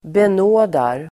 Uttal: [ben'å:dar]